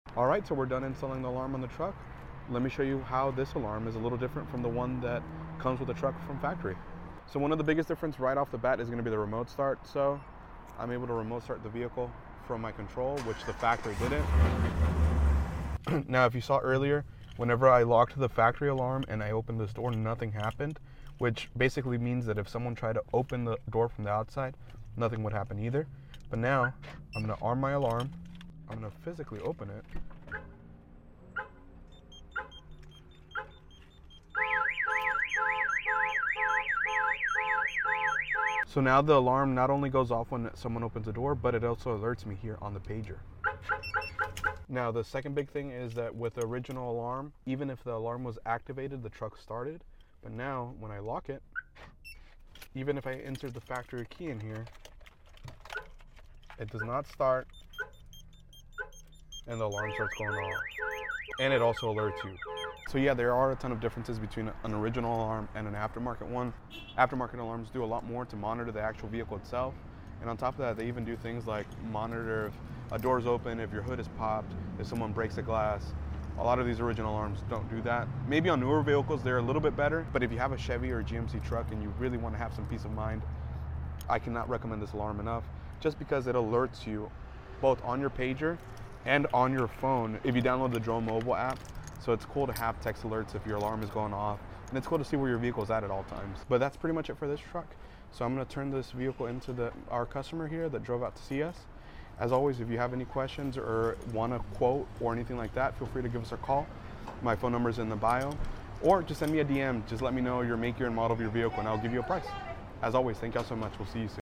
Heres an update on the silverado we installed this T12 on! As you can see when comparing this video to my last, the aftermarket alarm does much much much more to secure your vehicle!